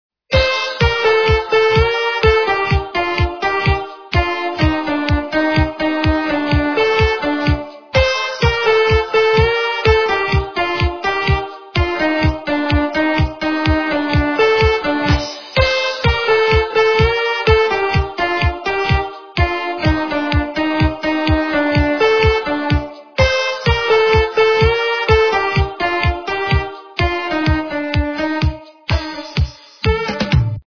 русская эстрада
полифоническую мелодию